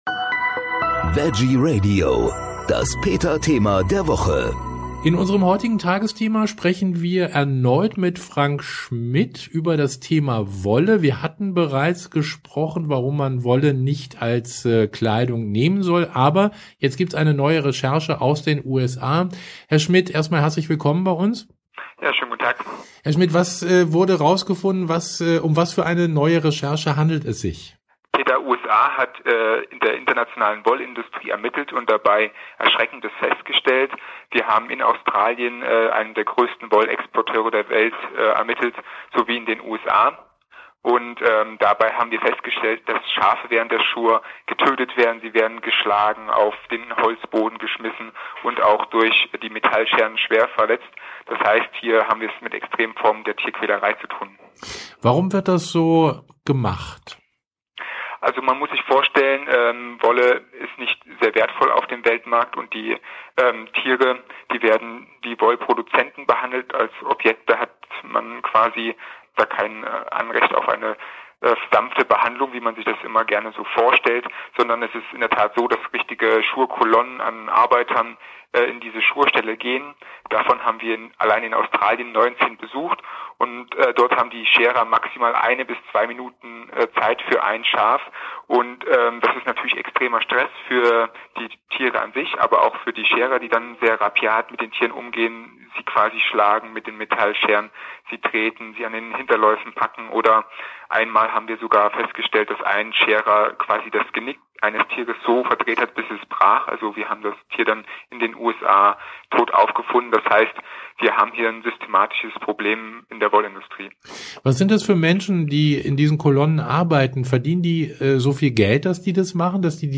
Peta Thema der Woche vom 18.07.14, Wolle. Gespräch